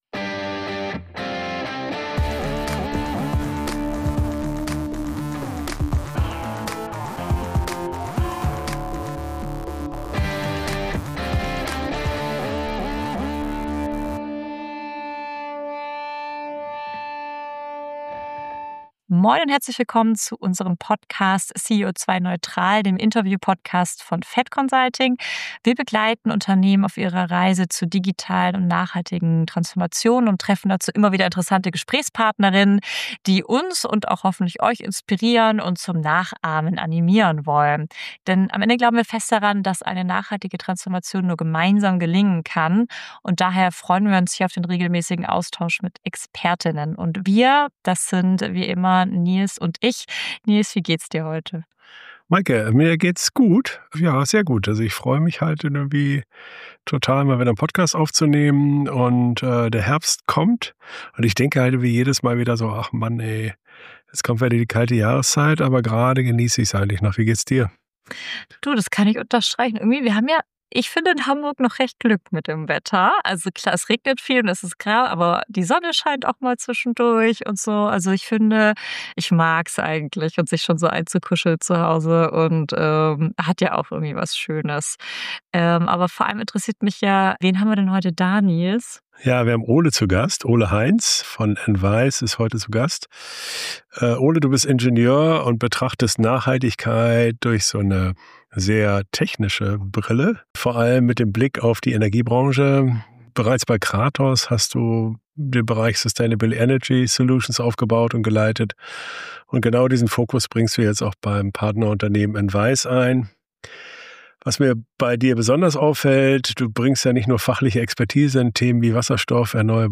Energieversorgung der Zukunft: Nachhaltige Energielösungen für Unternehmen ~ CEO2-neutral - Der Interview-Podcast für mehr Nachhaltigkeit im Unternehmen Podcast